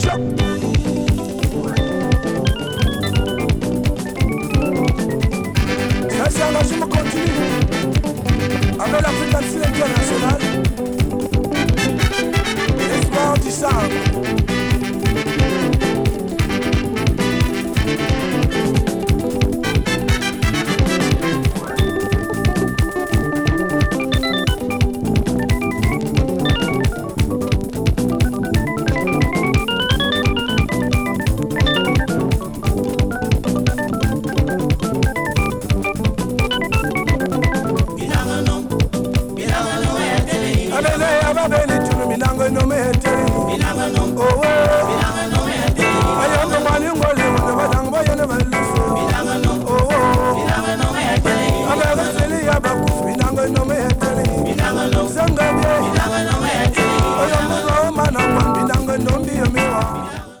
80s AFRO 踊る 詳細を表示する